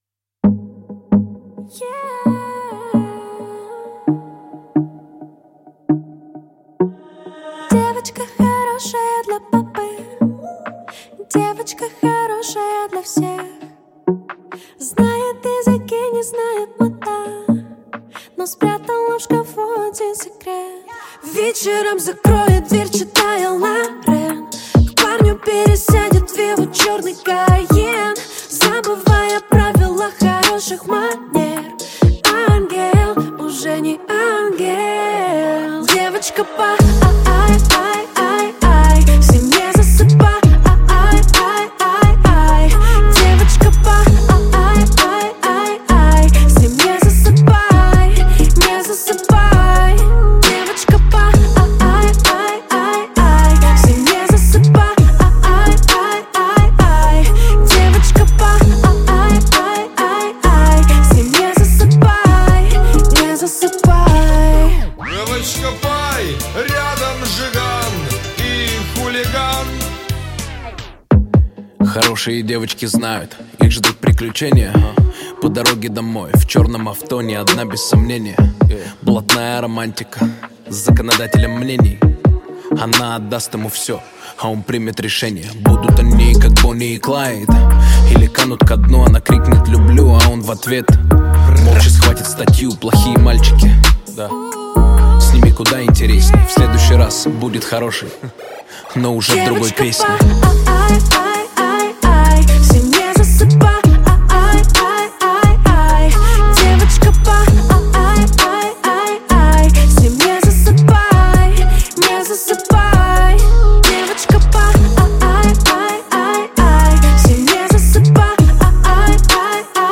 Поп-музыка
Жанр: Жанры / Поп-музыка